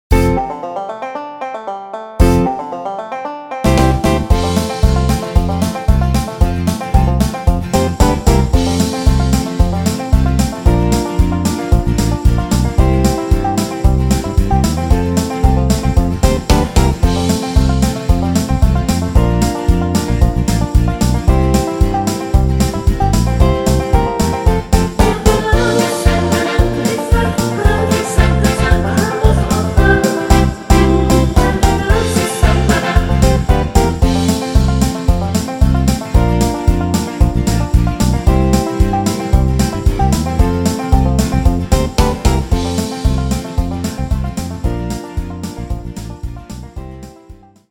version live